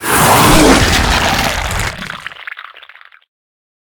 bite.ogg